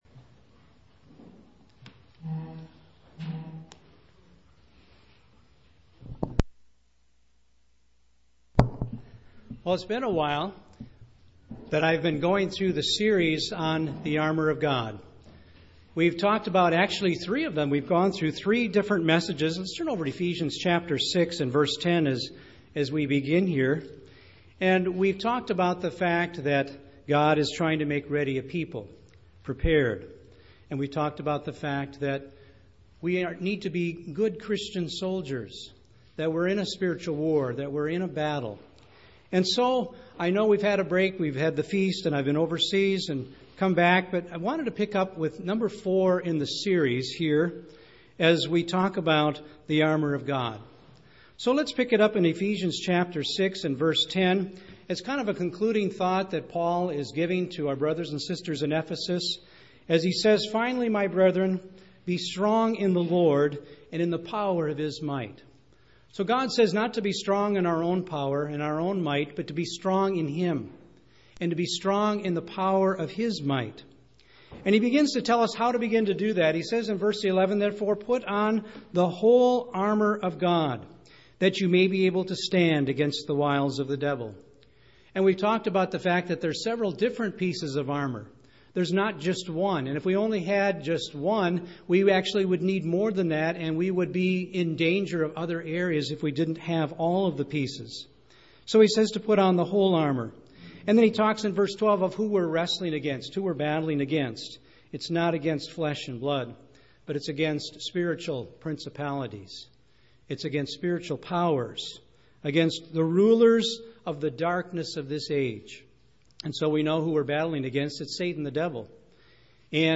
In this sermon we examine the metaphor of one piece of the armor of God: the shield of faith.